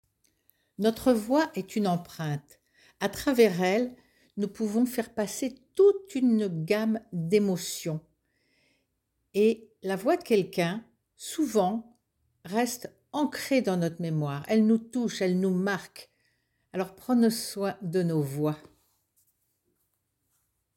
DEMO VOIX
- Mezzo-soprano